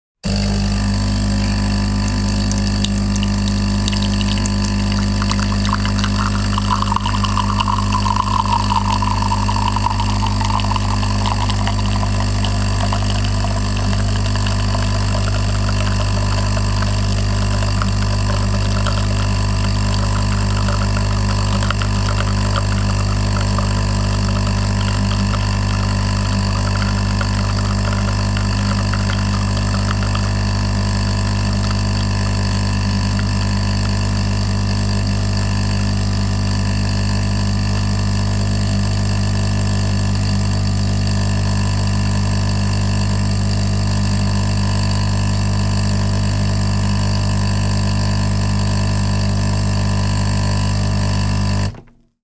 Espresso – Machine Pouring Hot Coffee, Buzzing Loudly
This sizzling sound effect drives home the passion of coffee-making by use of a retro, analog-sounding pour.
Systematic-Sound-Espresso-Machine-Pouring-Hot-Coffee_-Buzzing-Loudly.mp3